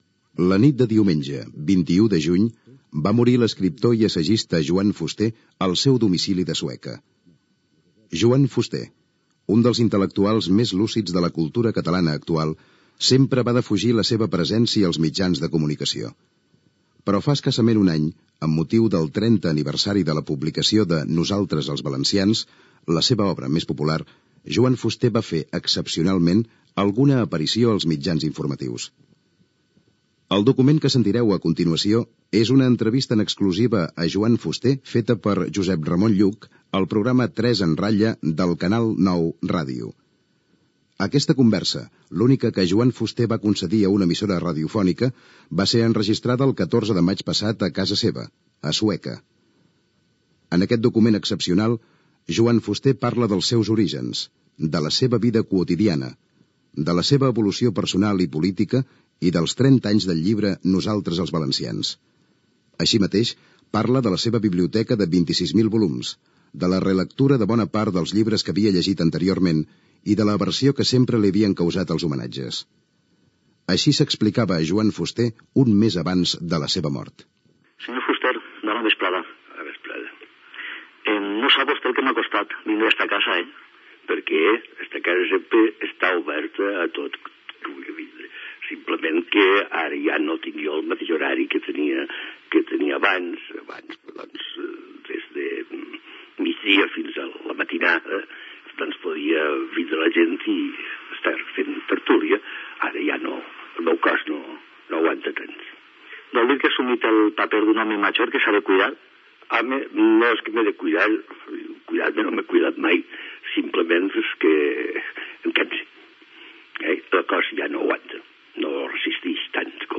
Gènere radiofònic Cultura
Banda FM